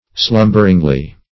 slumberingly - definition of slumberingly - synonyms, pronunciation, spelling from Free Dictionary
slumberingly - definition of slumberingly - synonyms, pronunciation, spelling from Free Dictionary Search Result for " slumberingly" : The Collaborative International Dictionary of English v.0.48: Slumberingly \Slum"ber*ing*ly\, adv.